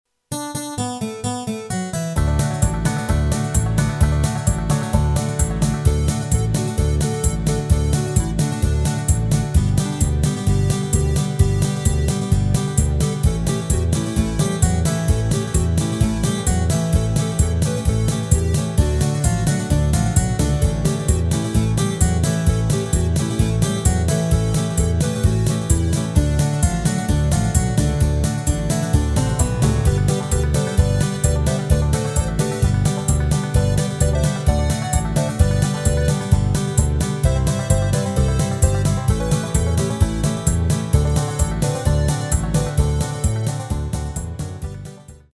Rubrika: Pop, rock, beat
Karaoke
Předehra: basa, kytara - a, h, cis, D